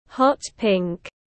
Màu hồng tươi tiếng anh gọi là hot pink, phiên âm tiếng anh đọc là /ˌhɒt ˈpɪŋk/.
Hot pink /ˌhɒt ˈpɪŋk/